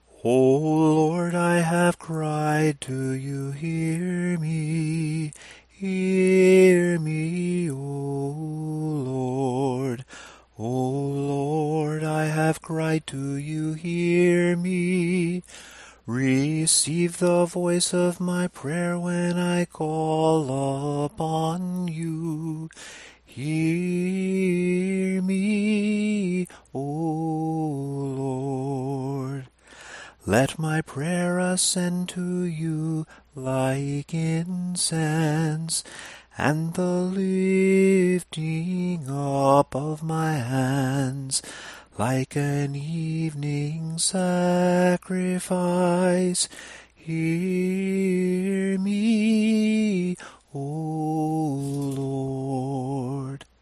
Here is how these verses are sung in Tone 2.
Tone_2_samohlasen_LIHC.mp3